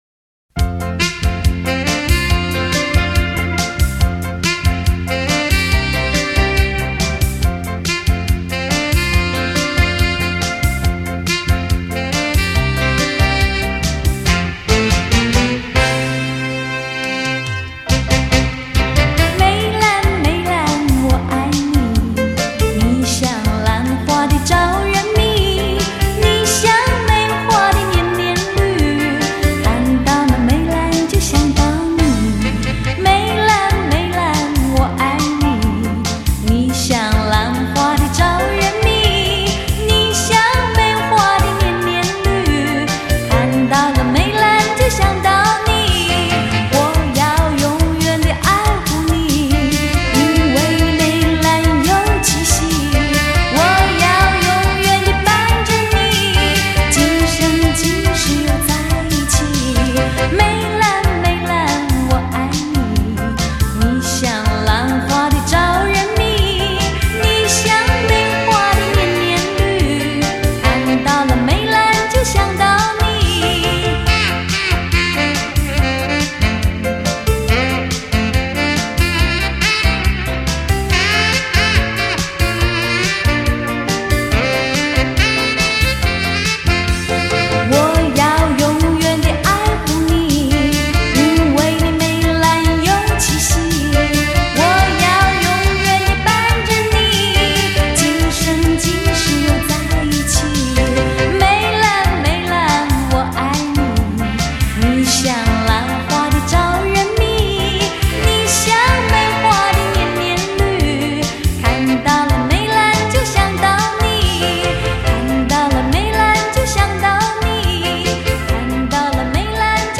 用温柔的歌声唤醒心中纯真的情感